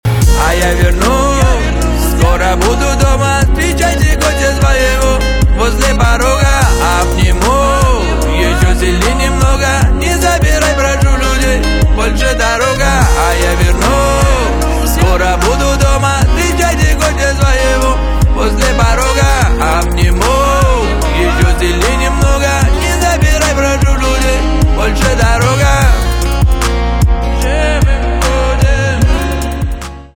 русский рэп
битовые , басы , кайфовые , гитара